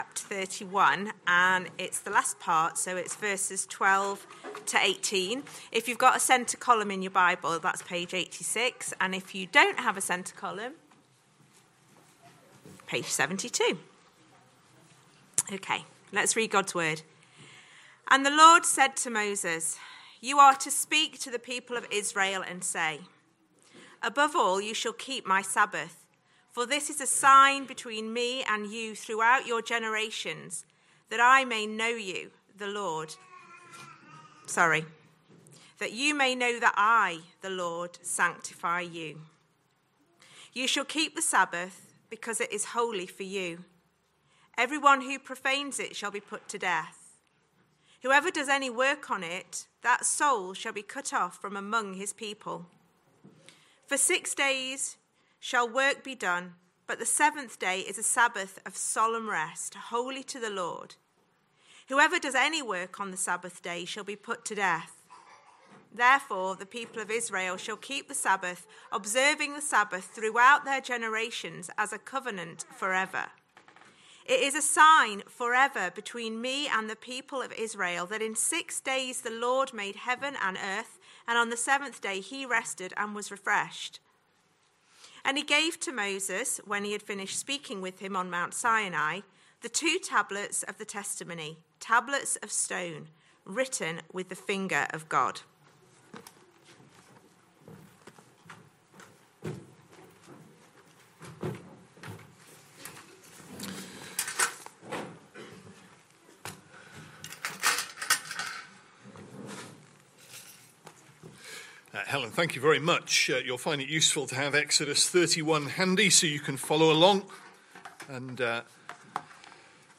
Sunday AM Service Sunday 9th November 2025 Speaker